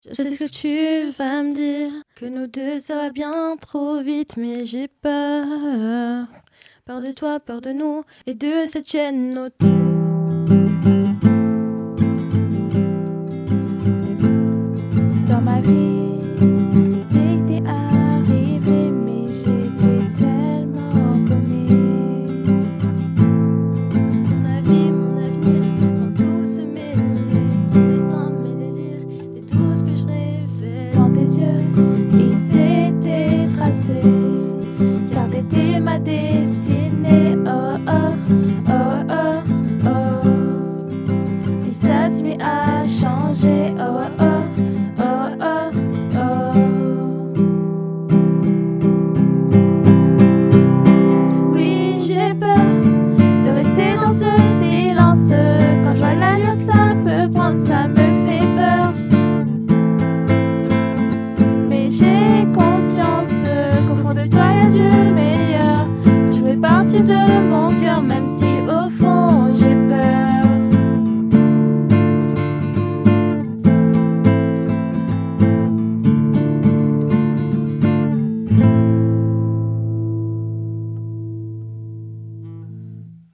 Une chanson qui explore les peurs et les doutes dans une relation intense, où l’amour se heurte aux silences, aux incertitudes et au risque de tout perdre.